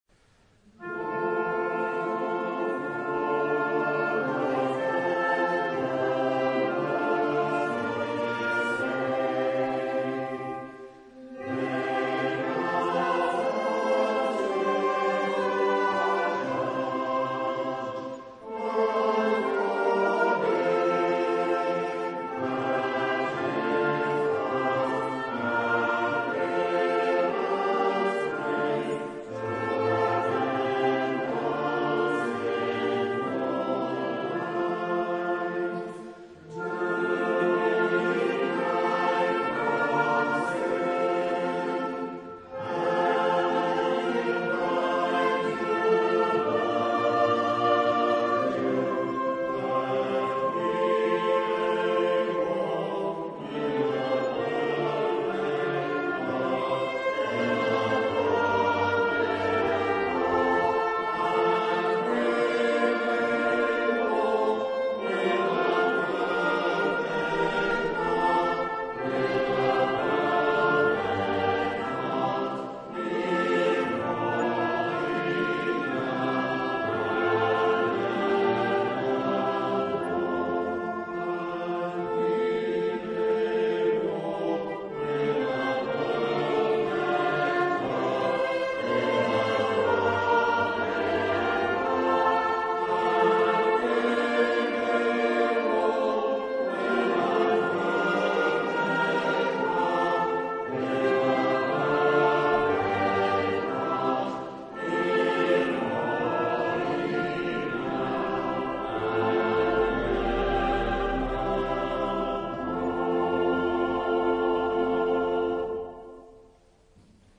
On 30th May, 2009, the Marsh Warblers, with invited friends, performed in costume in four parish churches on Romney marshes, in aid of church funds: Bonnington, Newchurch, St.Mary in the Marsh and Brookland.
Anthem: Lord for thy tender mercy's sake - music by Richard Farrant - 1min 58sec (916Kb)